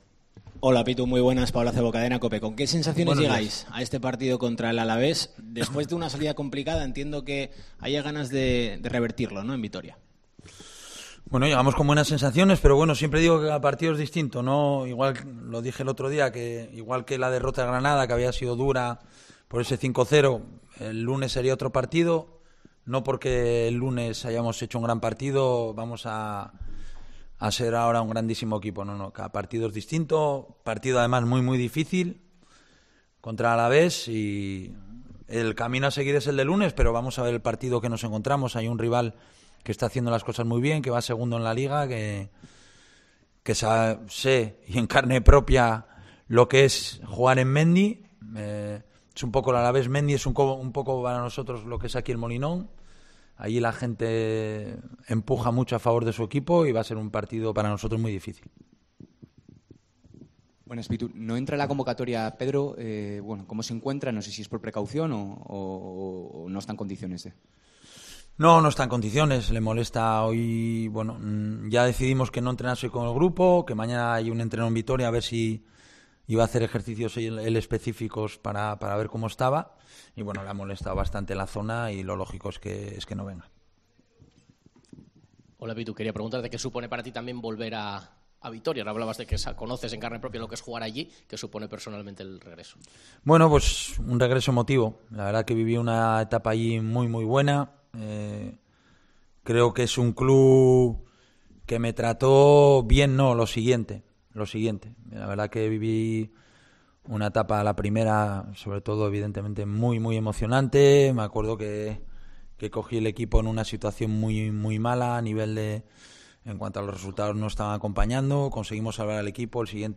El técnico del Sporting compareció en la sala de prensa de El Molinón antes del partido frente al Deportivo Alavés, en su regreso a Mendizorroza.